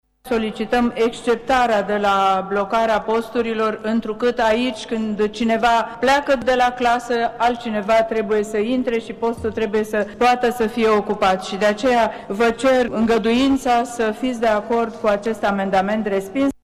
În timpul dezbaterilor, senatorii au votat, însă, şi un amendament care să le permită, în continuare, unităţilor de învăţământ preuniversitar şi superior, dar şi institutelor de cercetare să organizeze concursuri pentru ocuparea locurilor vacante. Senatorul PSD, Ecaterina Andronescu, a explicat decizia: